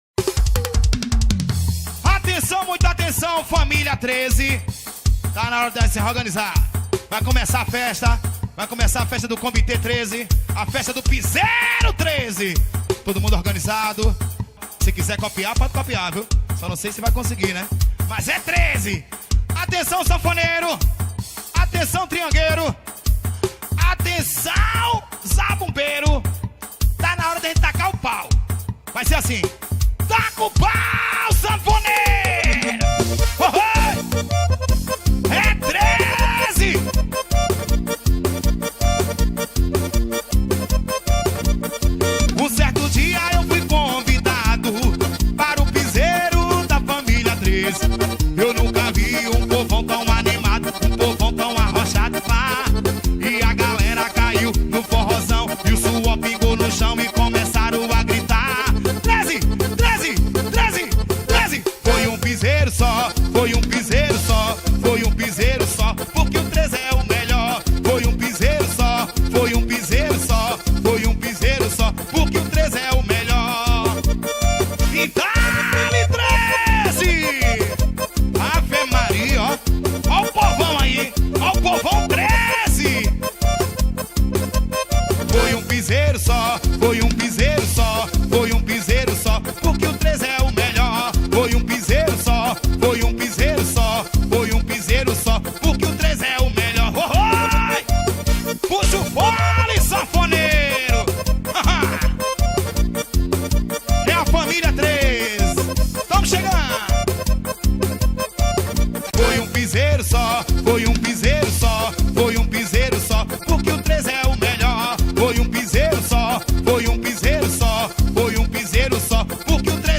2025-01-06 00:21:09 Gênero: Axé Views